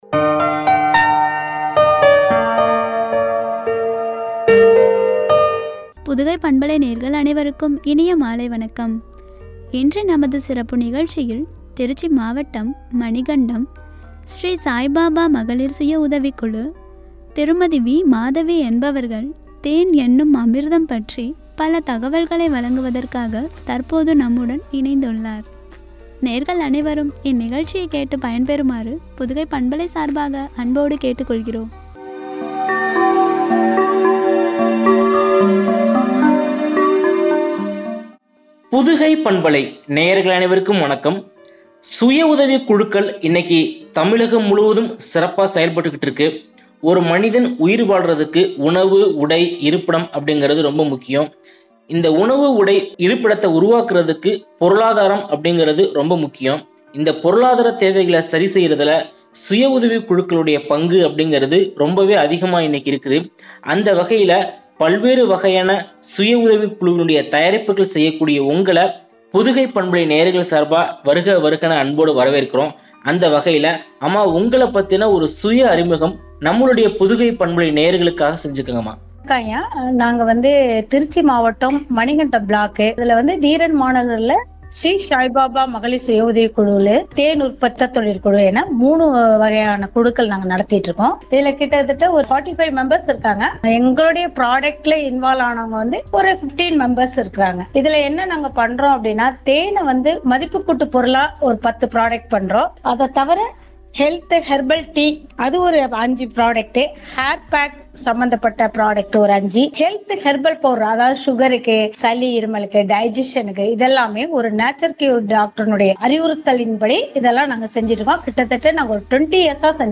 “தேன் எனும் அமிர்தம்” என்ற தலைப்பில் வழங்கிய உரை